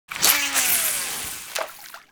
fishcast.wav